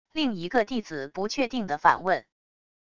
另一个弟子不确定的反问wav音频